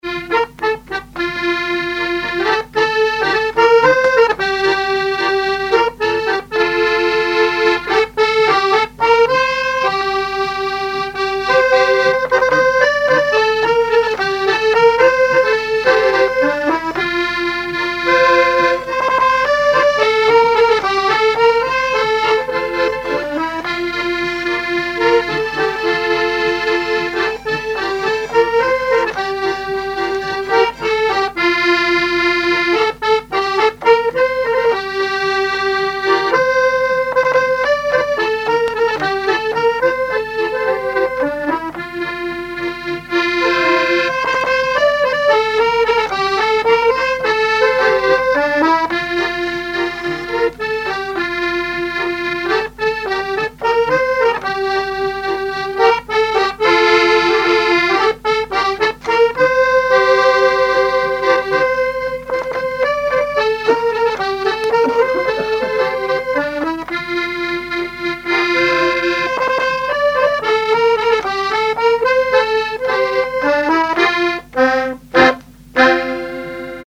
Fonction d'après l'analyste gestuel : à marcher
Genre laisse
Pièce musicale inédite